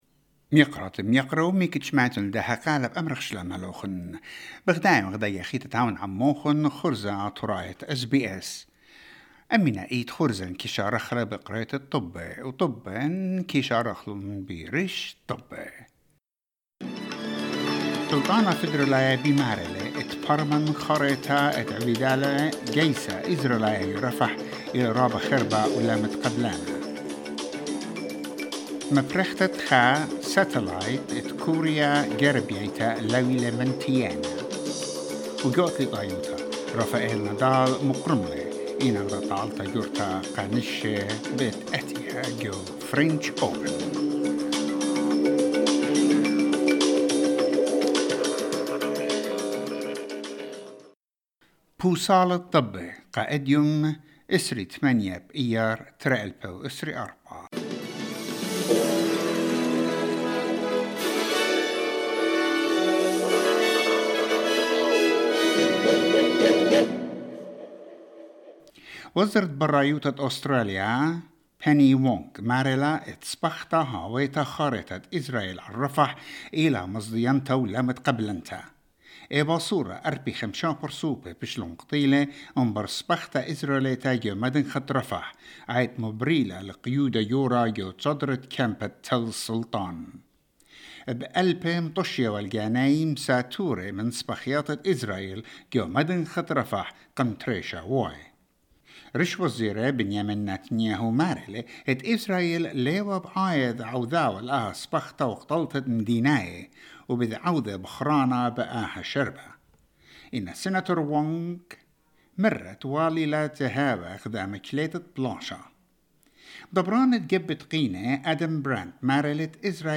SBS Assyrian news bulletin: 28 May 2024